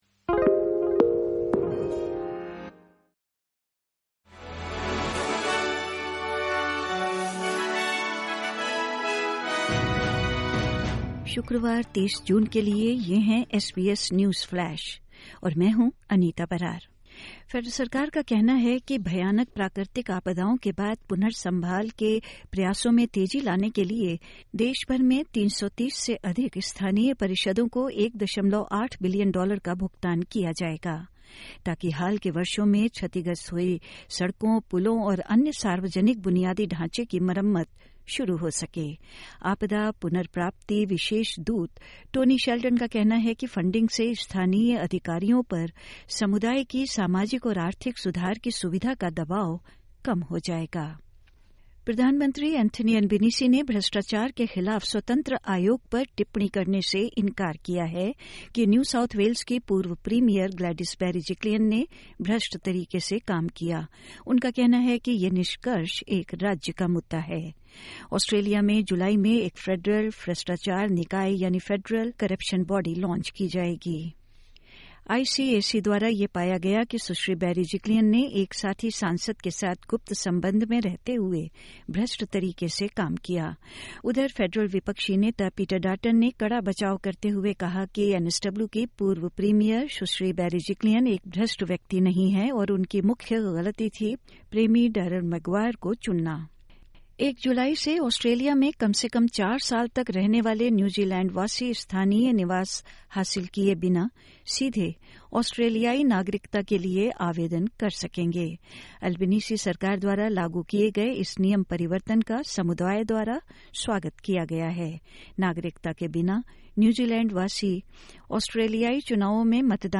SBS Hindi Newsflash 30 June 2023: National Anti-Corruption Commission begins its work